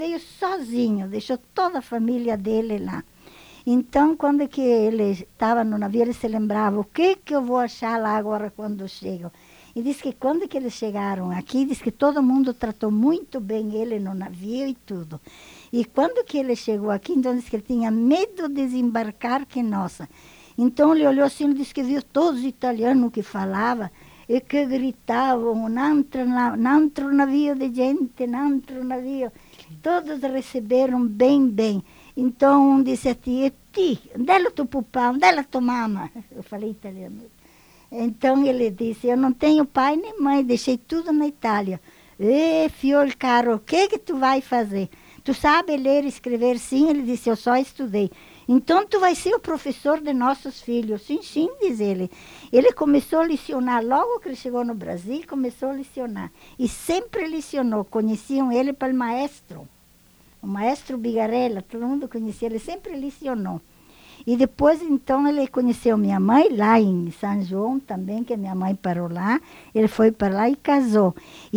Unidade Banco de Memória Oral